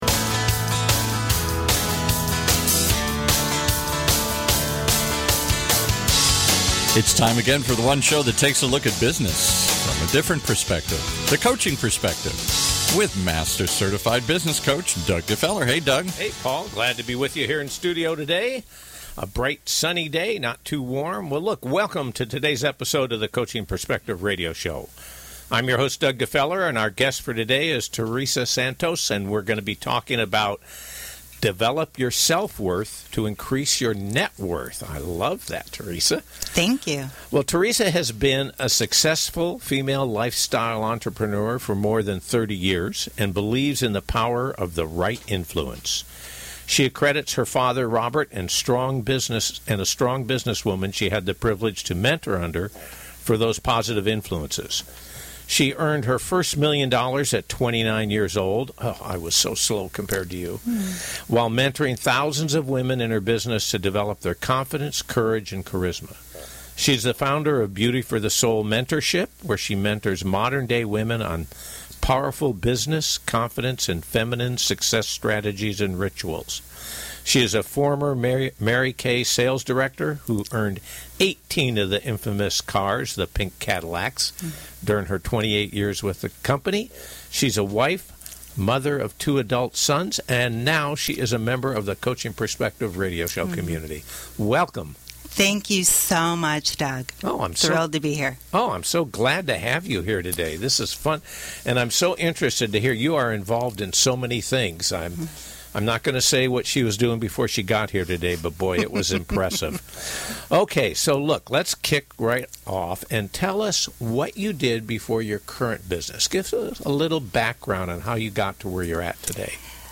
Recorded live on August 17, 2017 on The Coaching Perspective Radio Show.